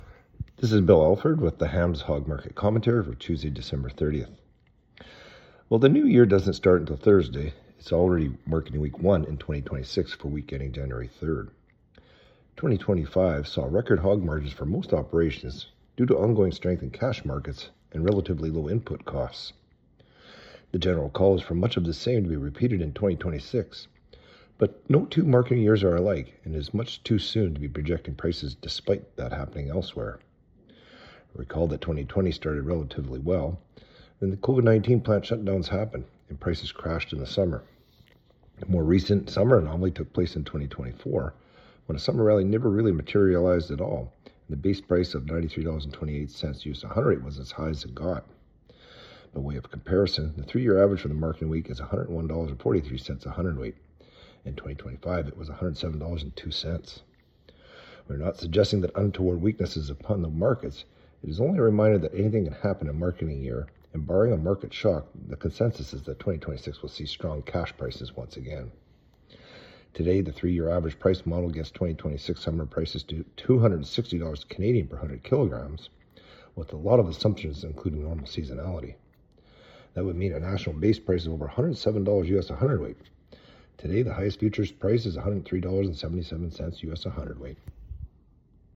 Hog-Market-Commentary-Dec.-30-25.mp3